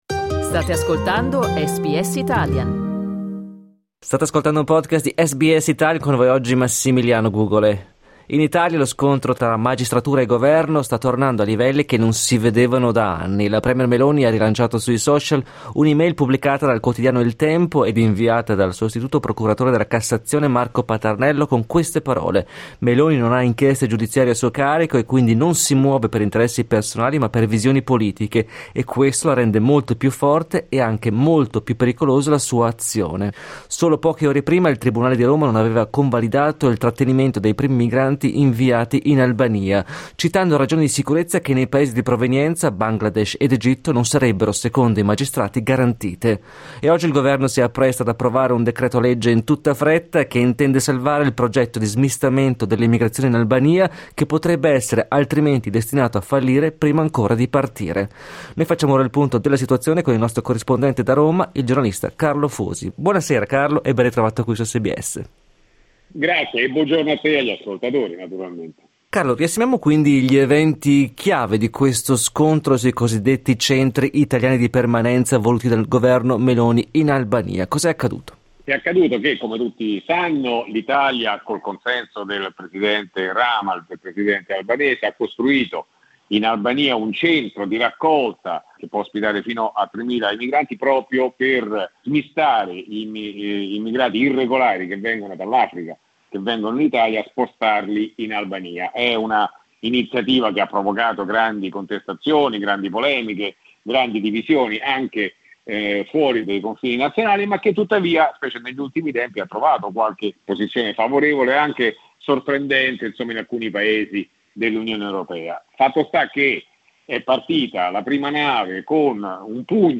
Ascolta l'analisi del nostro corrispondente da Roma